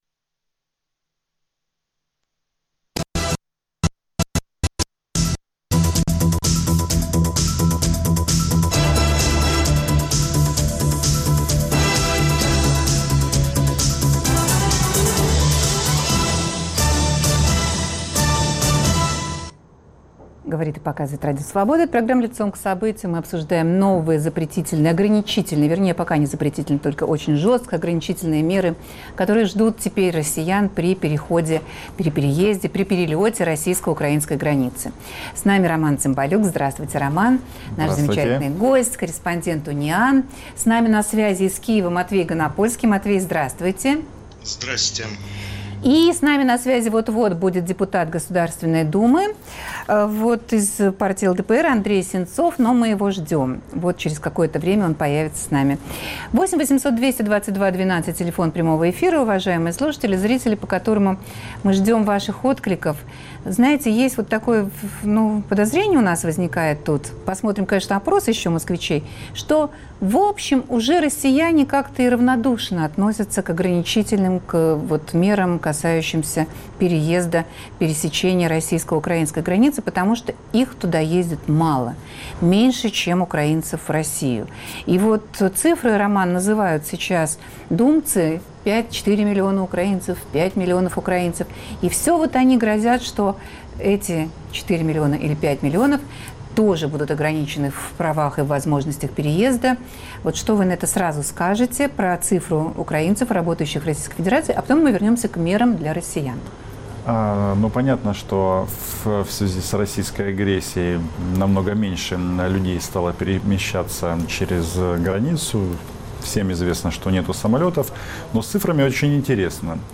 Дискуссия между украинскими журналистами